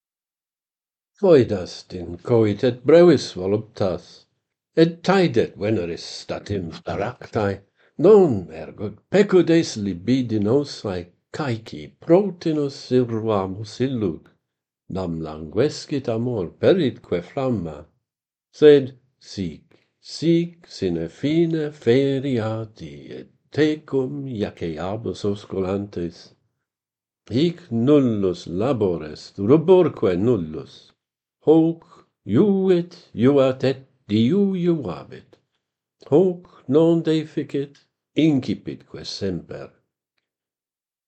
Kissing is better than sex - Pantheon Poets | Latin Poetry Recited and Translated